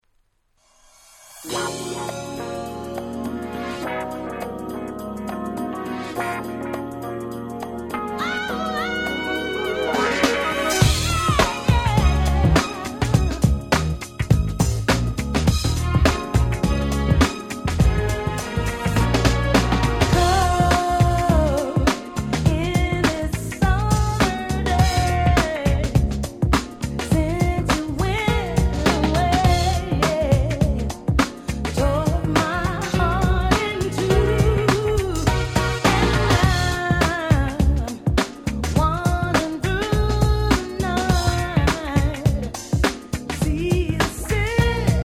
92' Nice R&B !!